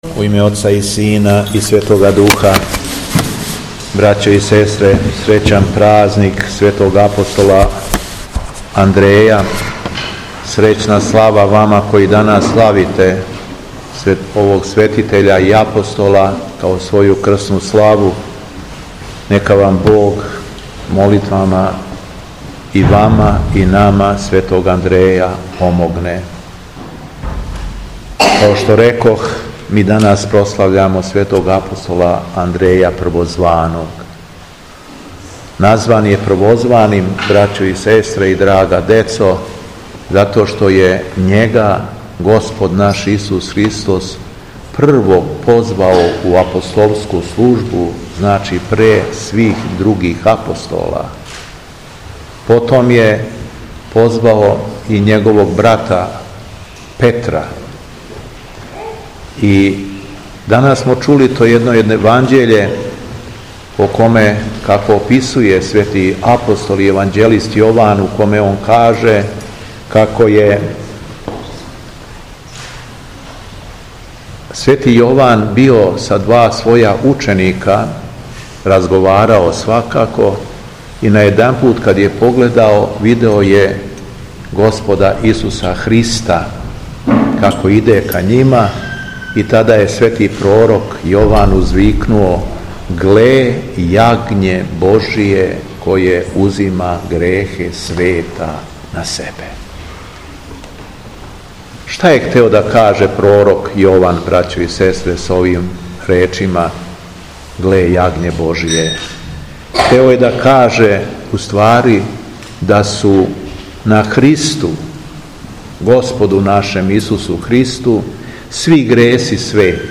Беседа Његовог Високопреосвештенства Митрополита шумадијског г. Јована
Након прочитаног јеванђељског зачала, свештенству и верном народу се беседом обратио Митрополит шумадијски Јован, рекавши: